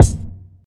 Kicks
rte_stk_kick.wav